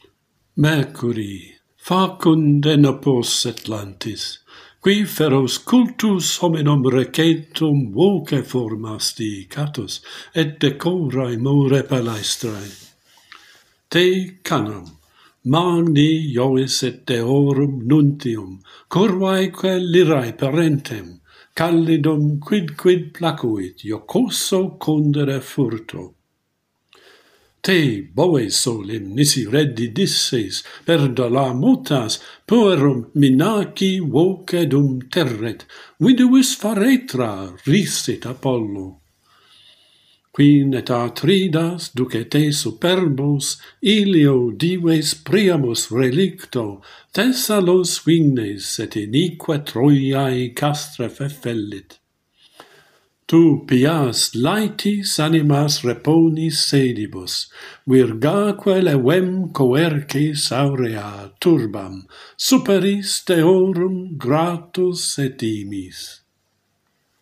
A prayer to Mercury - Pantheon Poets | Latin Poetry Recited and Translated